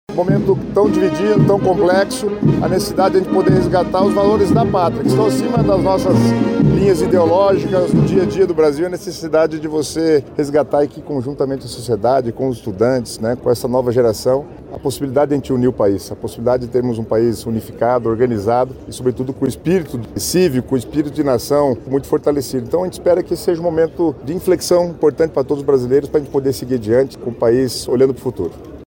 O secretário das Cidades, Guto Silva, representou o governo do estado e falou do simbolismo que a data carrega e também da necessidade de união da população.